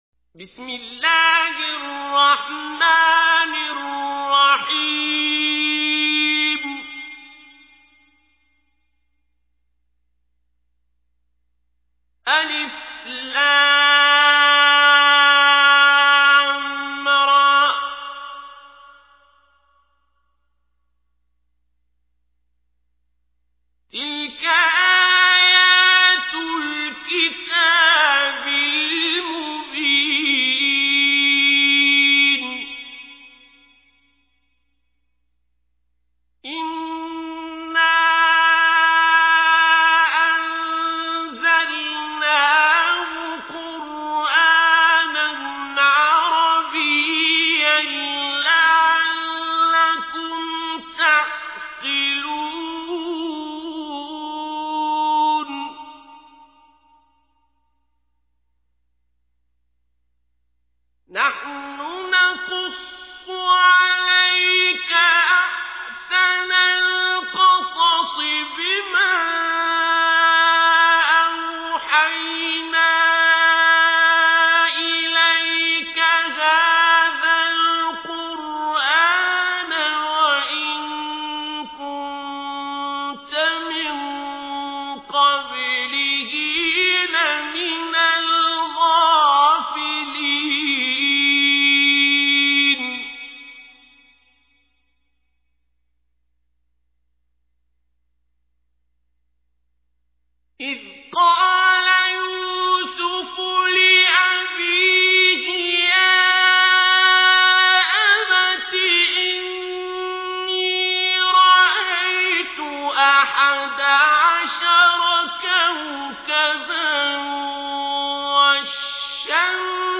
تحميل : 12. سورة يوسف / القارئ عبد الباسط عبد الصمد / القرآن الكريم / موقع يا حسين